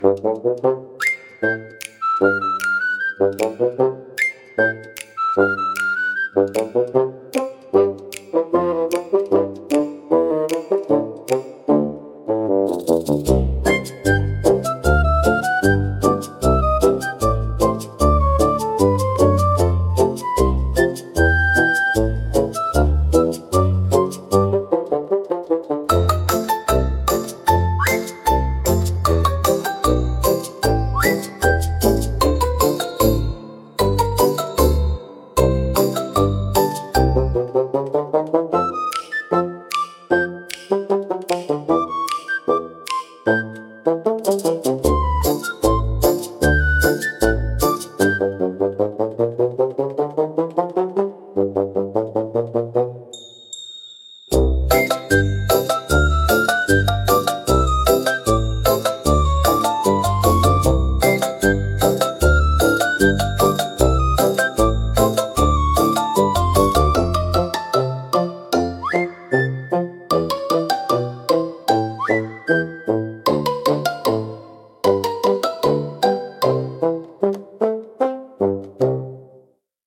BGMセミオーダーシステムおとぼけは、バスーンとシロフォンを主体としたコミカルでドタバタした音楽ジャンルです。
視聴者の笑いを誘い、軽快で親しみやすいムードを演出しながら、退屈を吹き飛ばします。